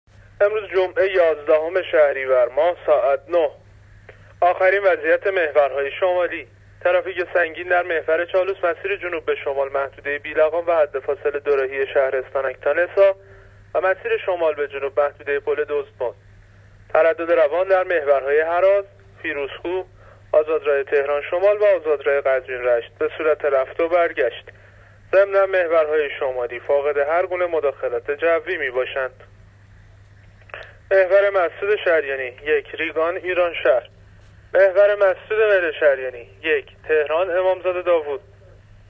گزارش رادیو اینترنتی از آخرین وضعیت ترافیکی جاده‌ها تا ساعت ۹ یازدهم شهریور؛